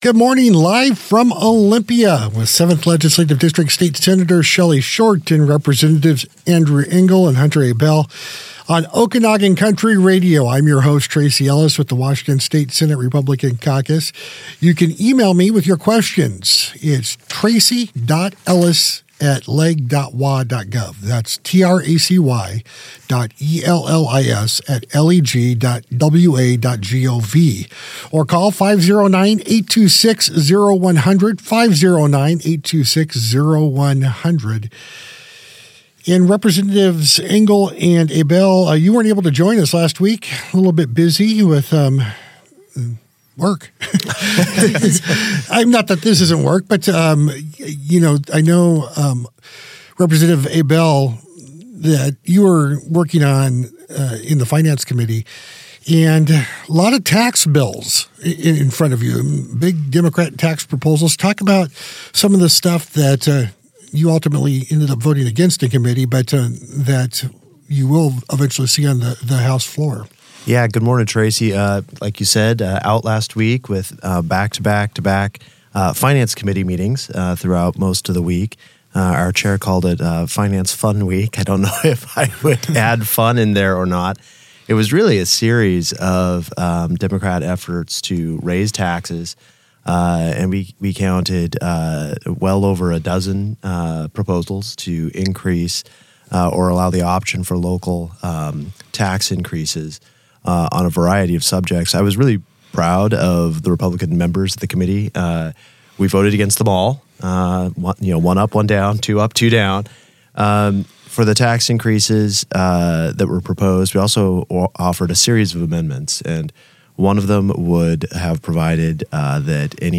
SRC/HRC On Air: Sen. Shelly Short and Reps. Andrew Enegell and Hunter Abell on KOMW in Omak - Senate Republican Caucus